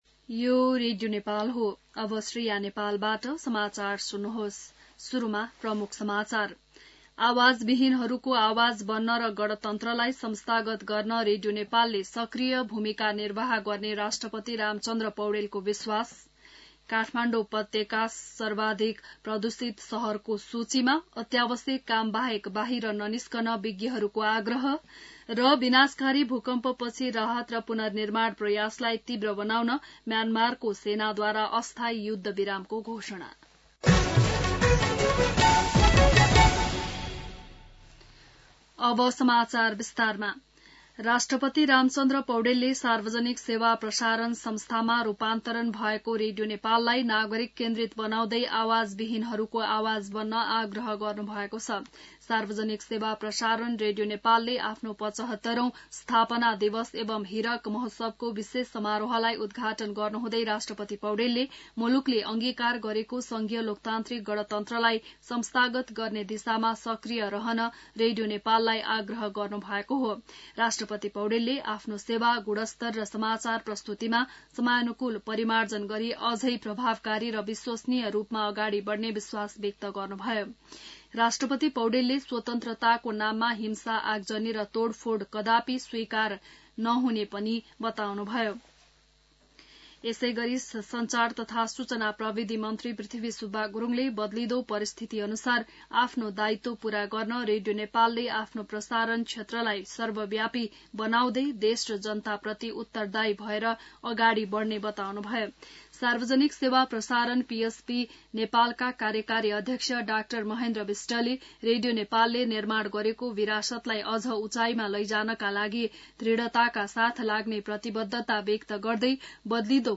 An online outlet of Nepal's national radio broadcaster
बिहान ९ बजेको नेपाली समाचार : २१ चैत , २०८१